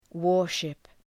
Shkrimi fonetik {‘wɔ:r,ʃıp}
warship.mp3